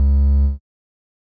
Techmino/media/sample/bass/5.ogg at beff0c9d991e89c7ce3d02b5f99a879a052d4d3e
添加三个简单乐器采样包并加载（之后用于替换部分音效）